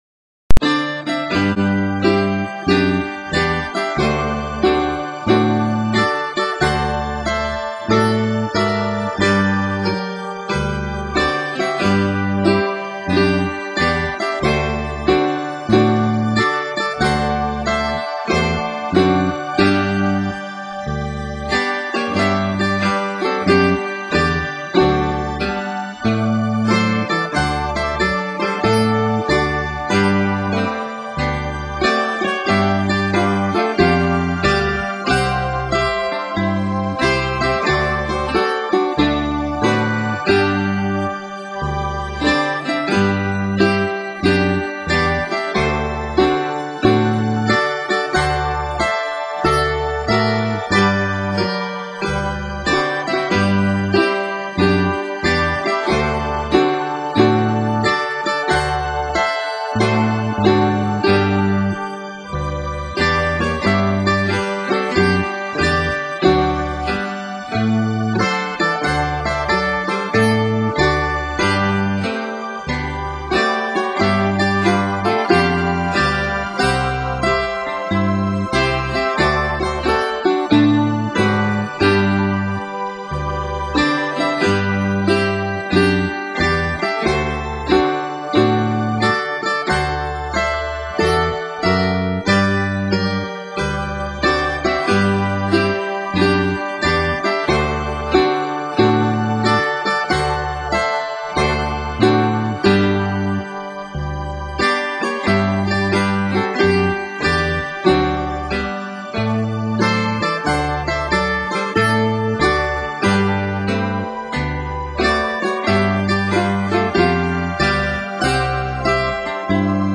tocados e orquestrados